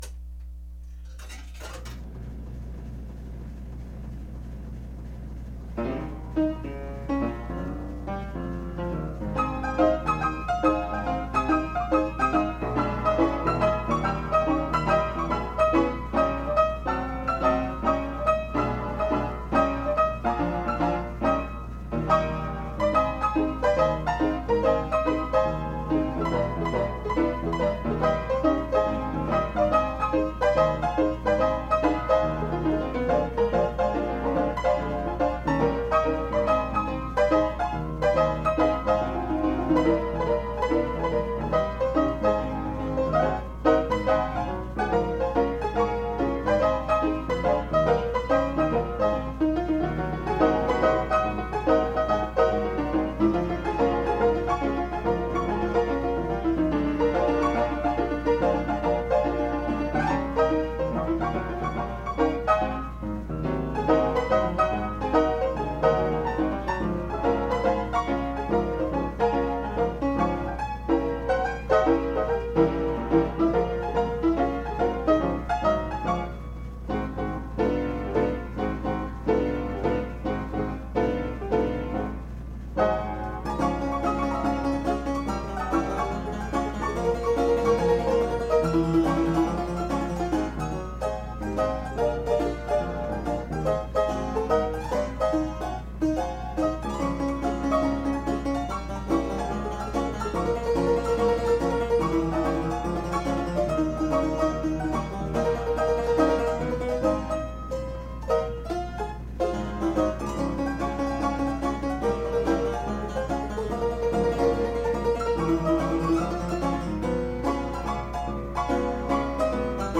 Seek Tune Composers on Early "A" Rolls
Associates [circa 1973], to accompany a small Ragtime pop-up exhibit
Attached are the MP3 audio files being played on a Seeburg 'L' from